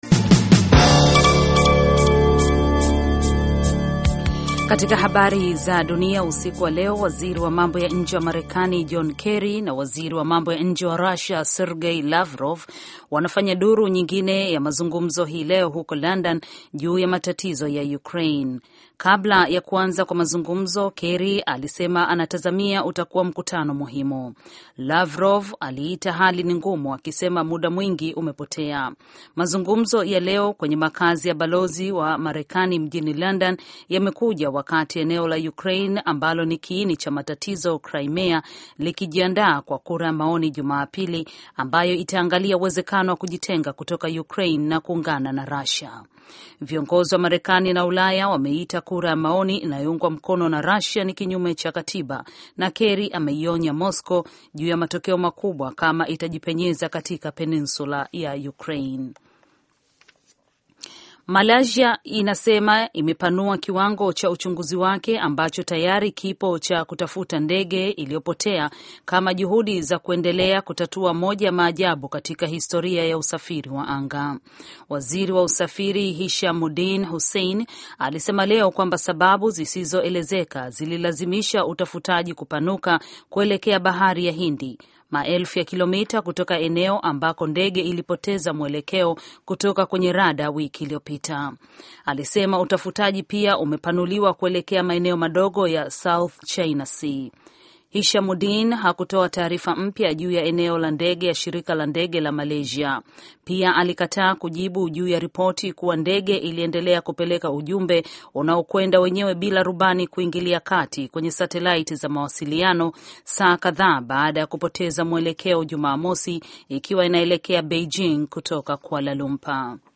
Taarifa ya Habari VOA Swahili - 5:55